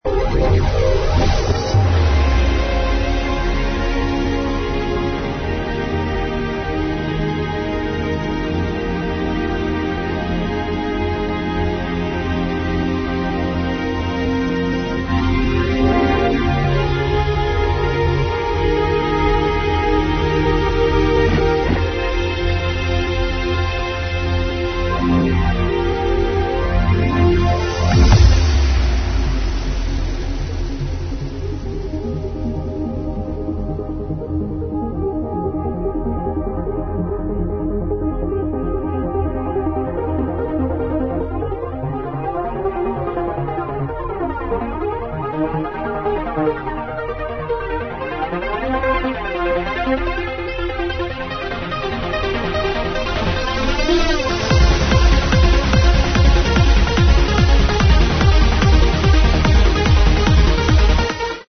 A brazilian dj plays this track